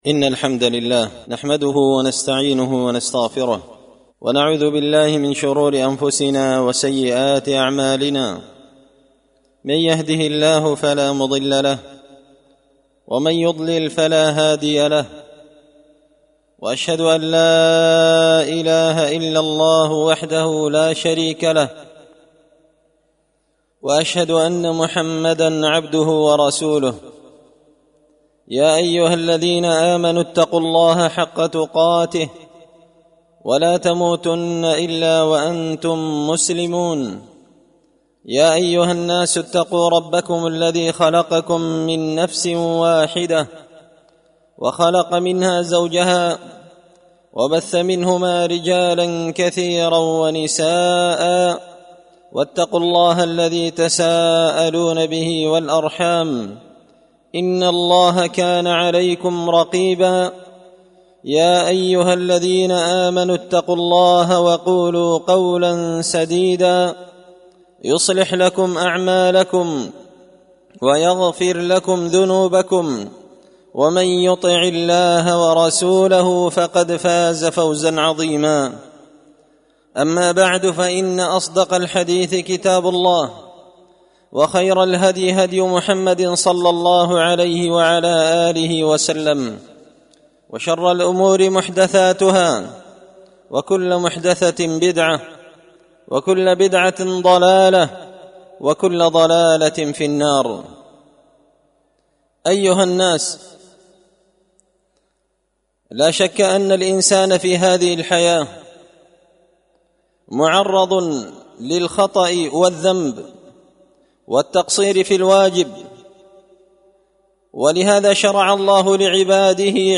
خطبة جمعة بعنوان – عبادة الاستغفار
دار الحديث بمسجد الفرقان ـ قشن ـ المهرة ـ اليمن